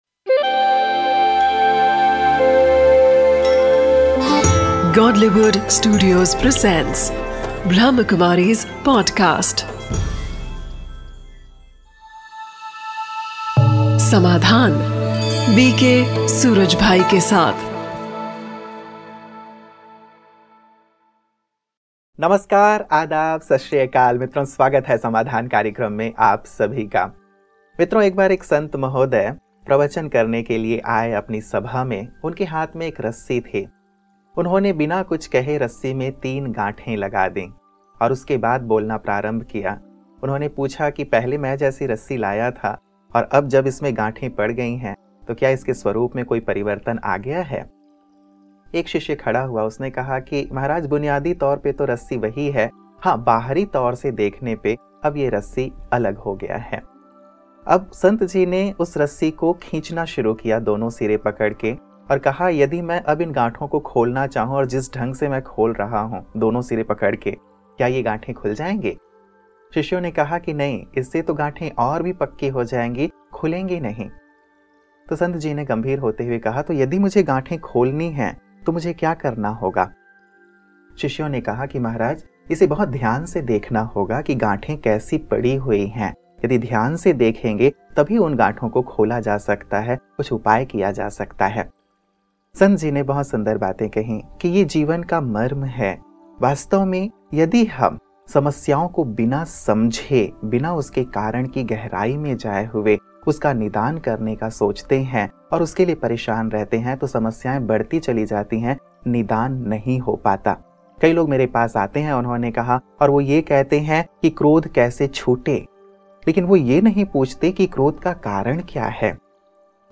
‘Bol Anmol’ is a series of lectures brimming with spiritual essence